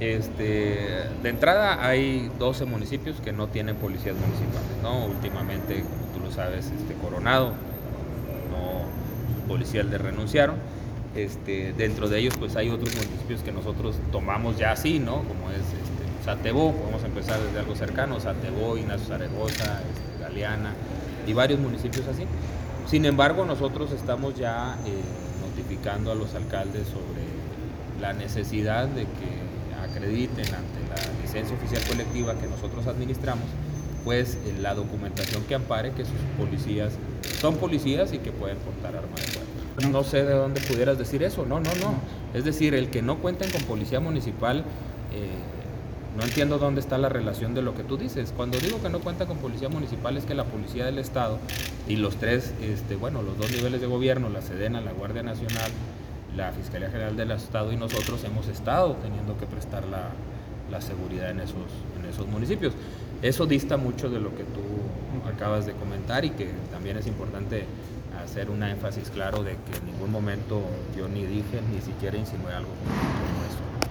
Audios. Gilberto Loya Chávez, secretario de Seguridad Pública del Estado.